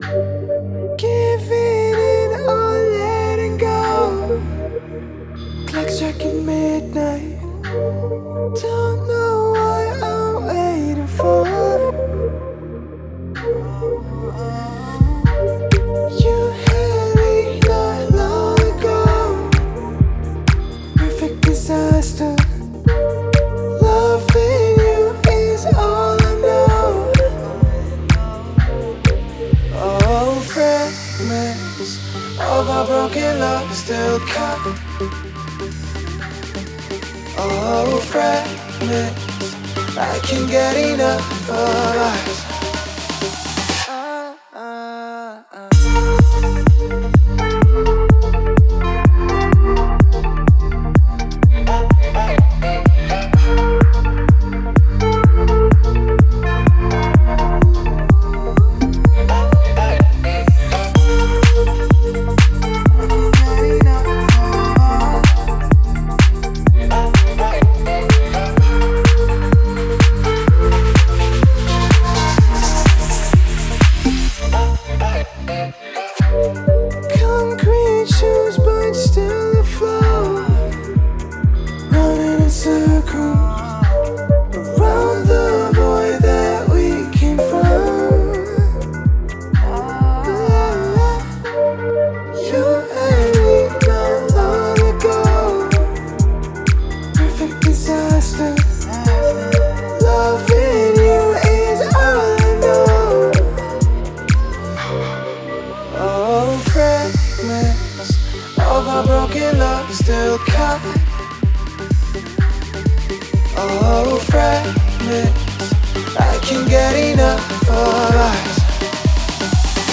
атмосферная и меланхоличная композиция
выполненная в жанре электроника с элементами эмбиента.